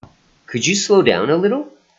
音声もつけていますので、発音をよく聞いて真似してみてください。